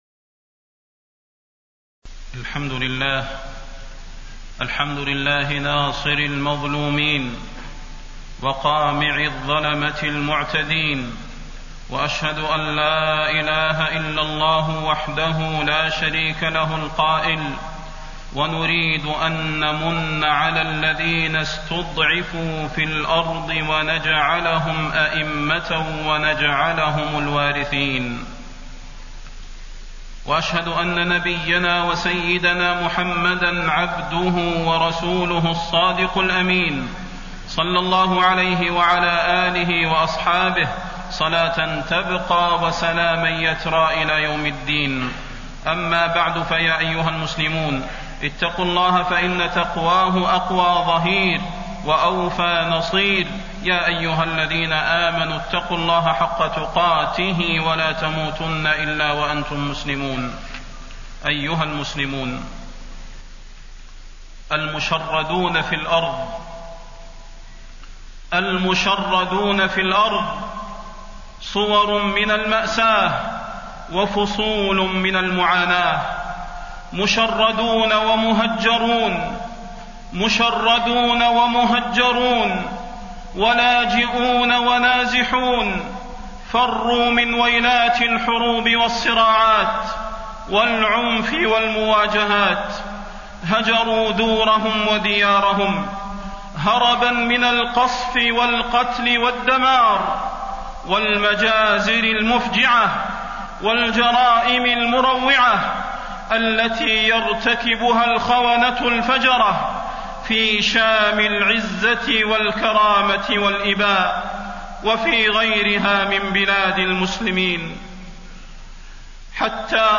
تاريخ النشر ٦ ربيع الأول ١٤٣٤ هـ المكان: المسجد النبوي الشيخ: فضيلة الشيخ د. صلاح بن محمد البدير فضيلة الشيخ د. صلاح بن محمد البدير أنين سوريا